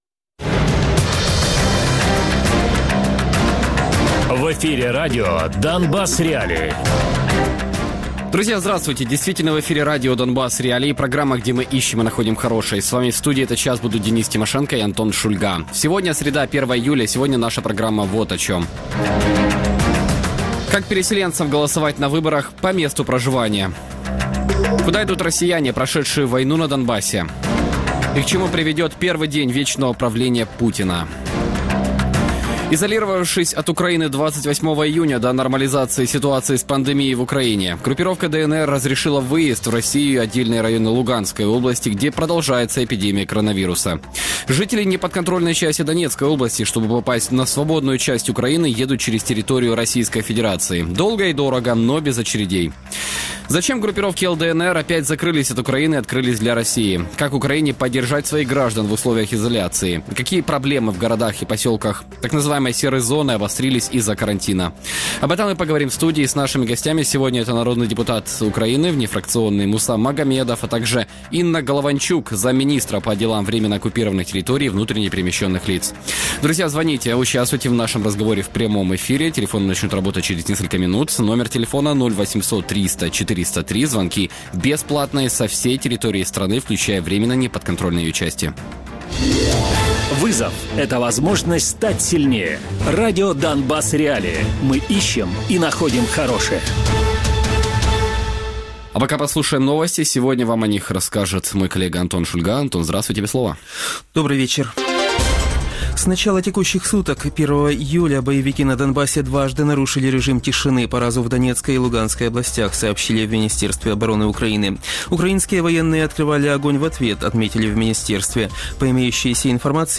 Гості програми: Муса Магомедов - народний депутат України, позафракційний та Інна Голованчук - заступник міністра у справах тимчасово окупованих територій і внутрішньо переміщених осіб (МінТОТ). Радіопрограма «Донбас.Реалії» - у будні з 17:00 до 18:00.